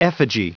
Prononciation du mot effigy en anglais (fichier audio)
Prononciation du mot : effigy